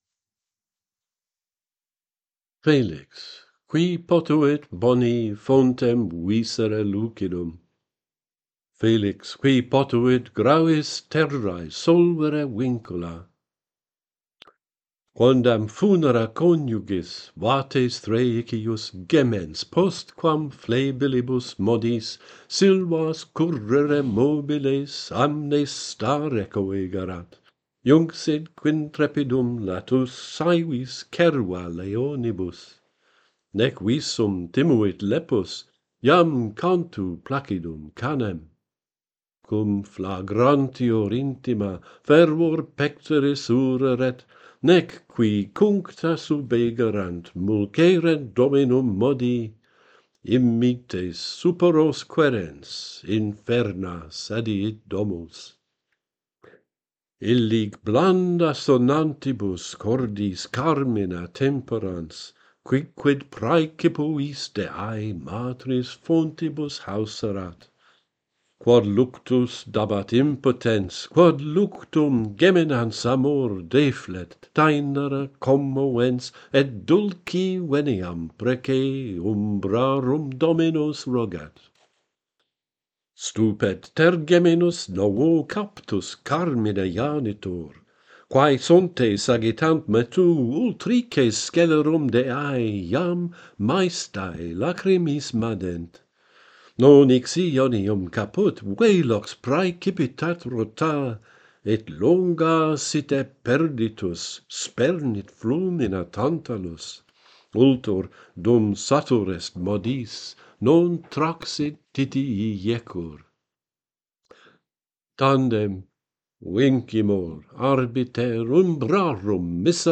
Orpheus and Eurydice by Boethius - Pantheon Poets | Latin Poetry Recited and Translated